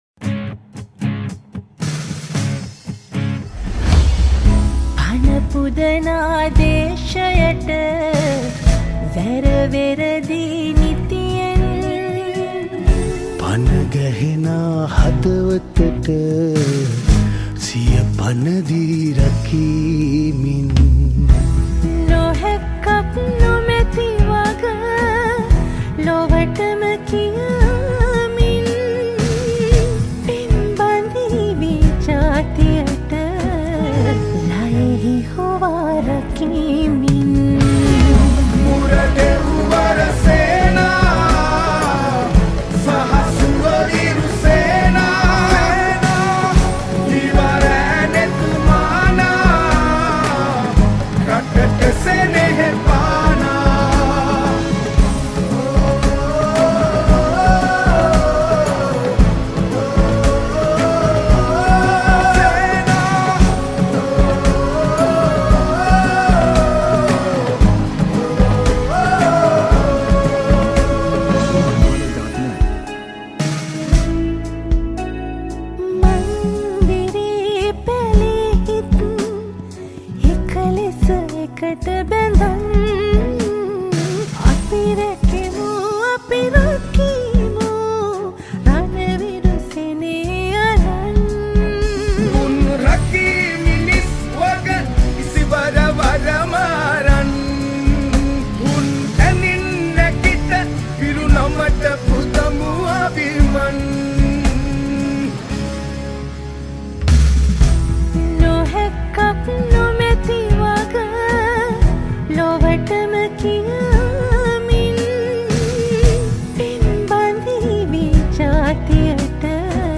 Guitar
Percussions